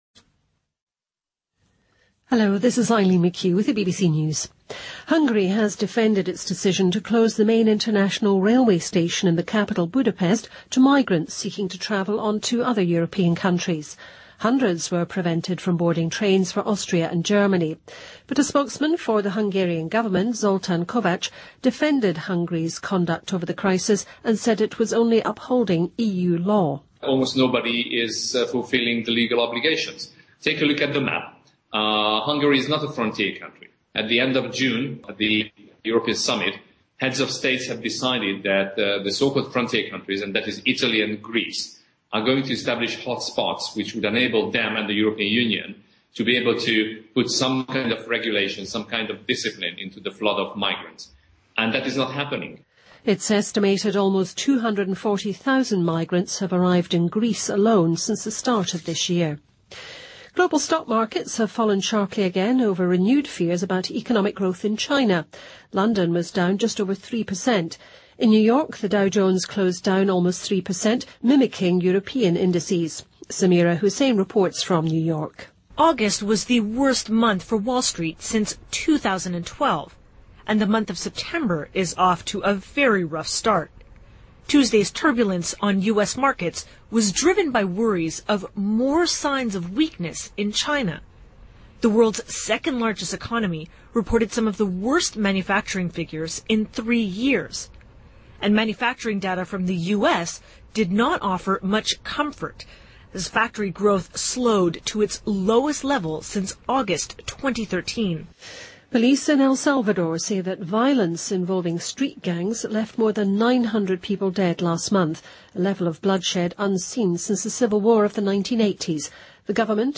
BBC news,担心中国经济 全球股市再次大跌
日期:2015-09-06来源:BBC新闻听力 编辑:给力英语BBC频道